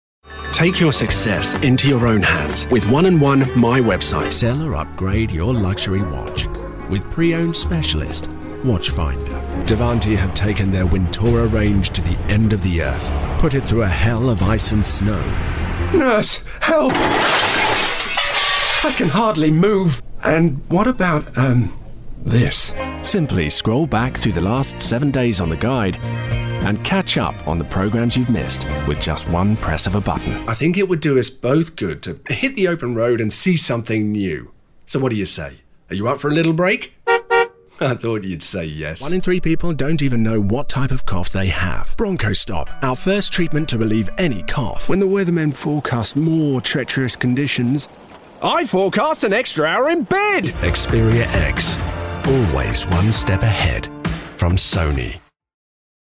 Demo
Adult
Has Own Studio
british rp | natural
ANIMATION 🎬